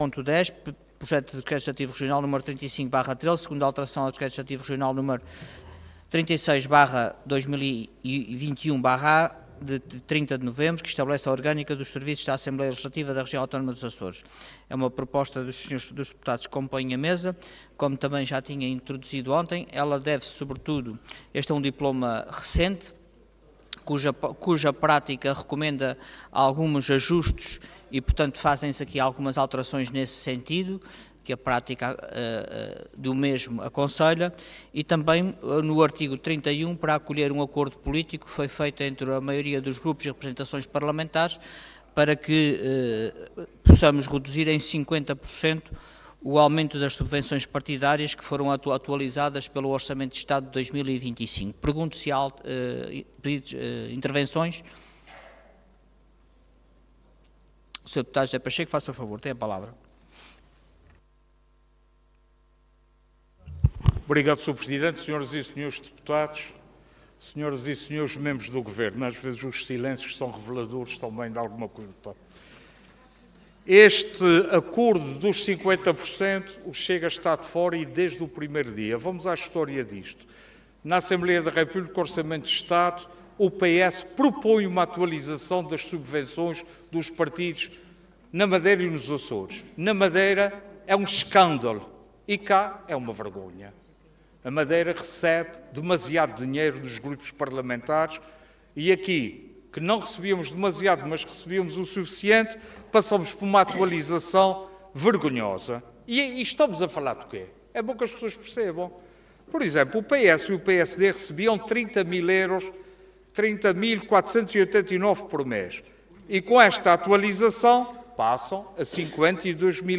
Intervenção
Orador Luís Garcia Cargo Presidente da Assembleia Regional Entidade ALRAA